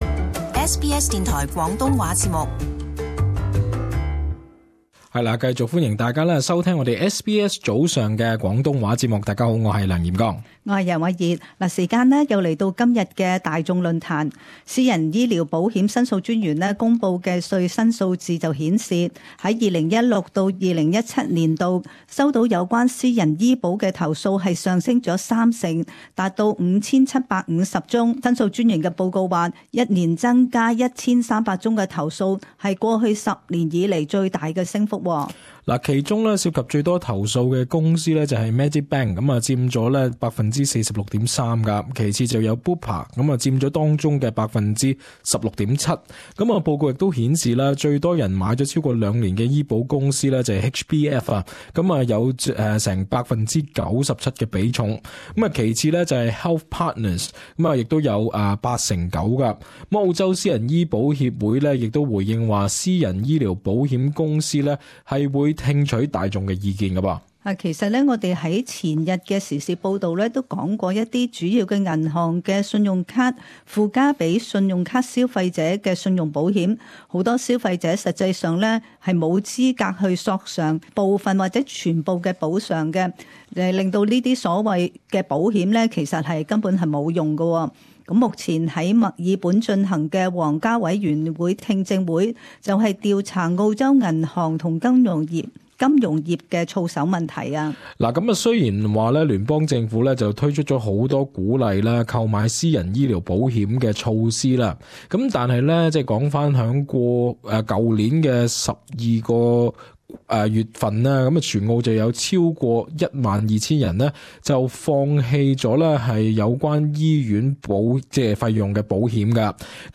Talkback: Is buying private health insurance worth it?